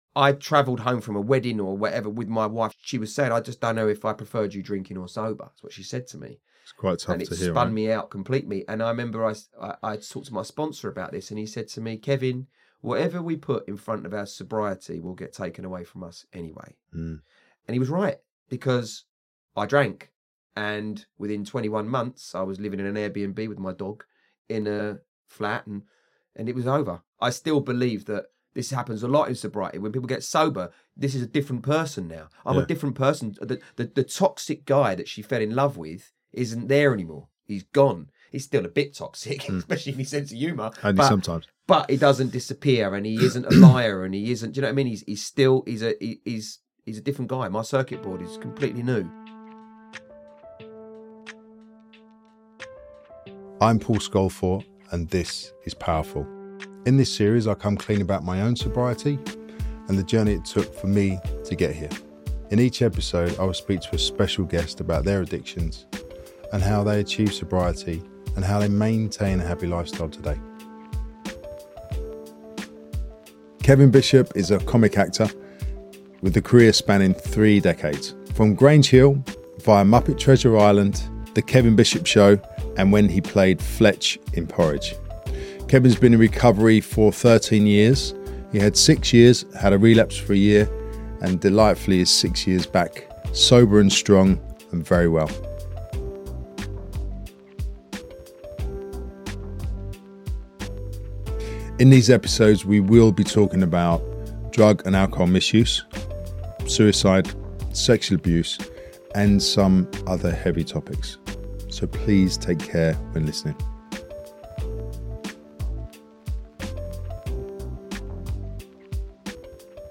This episode has its fair share of laugh aloud moments as Kevin shares his journey with sincerity and wry self-reflection.